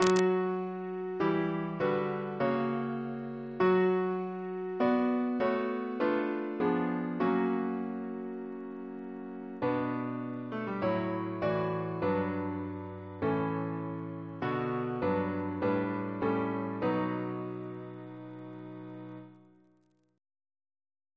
Double chant in B minor Composer: Chris Biemesderfer (b.1958)